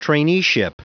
Prononciation du mot traineeship en anglais (fichier audio)
Prononciation du mot : traineeship